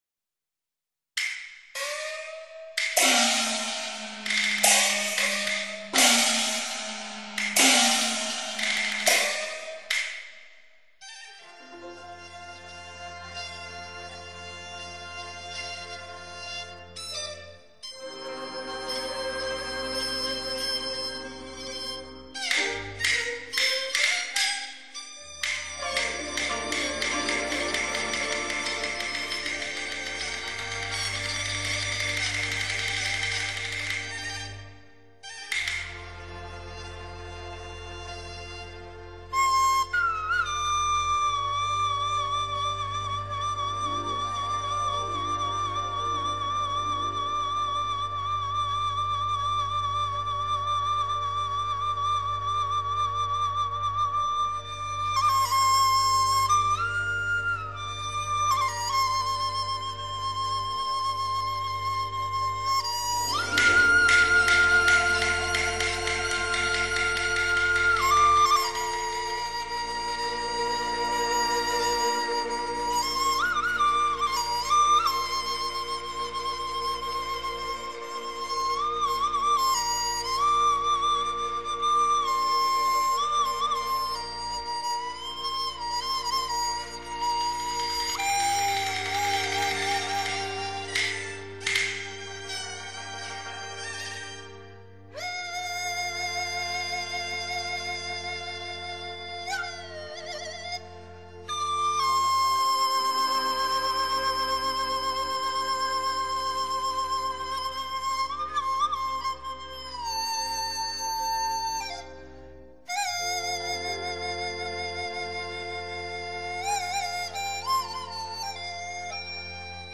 笛子版的京腔“逍遥津